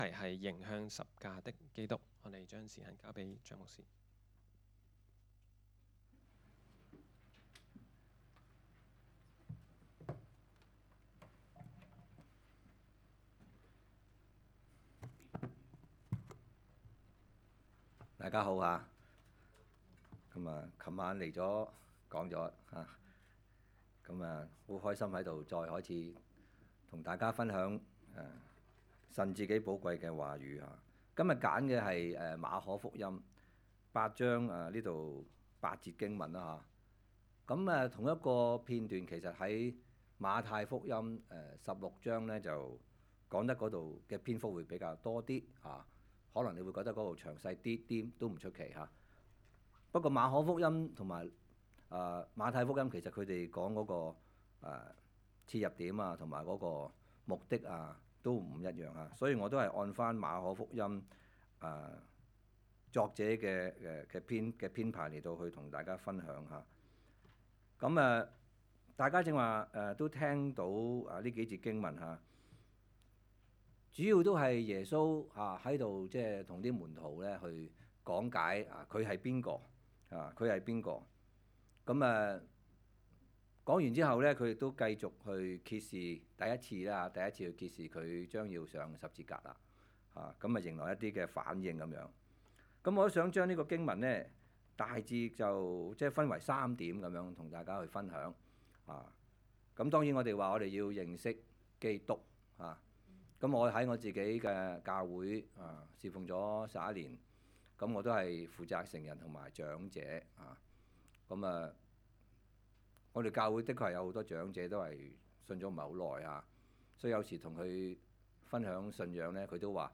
2025年11月15日及16日講道